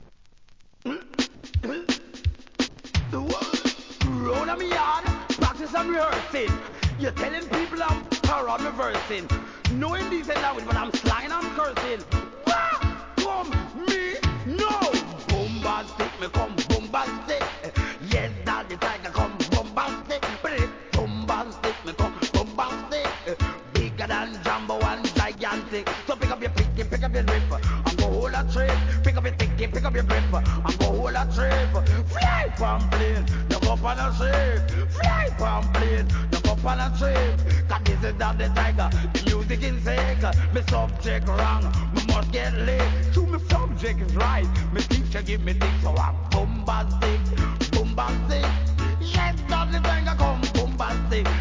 REGGAE
ベースが最高に気持がイイ